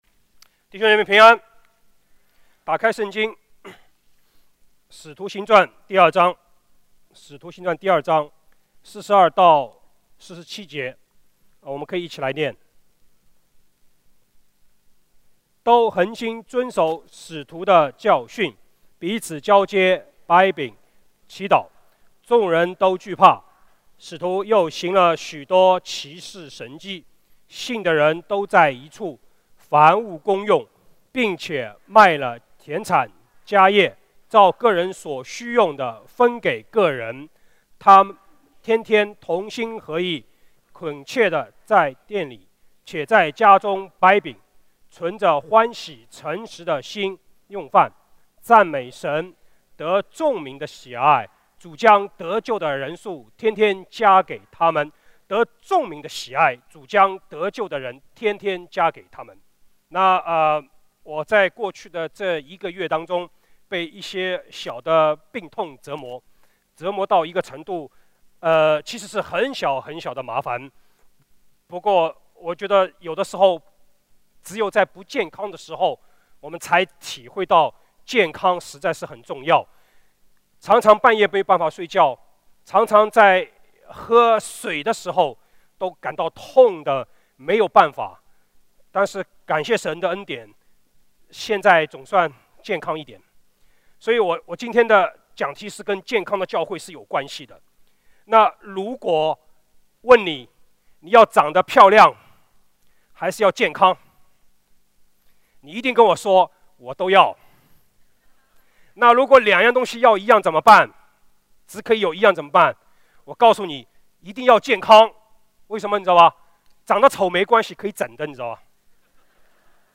主日证道｜往哪里去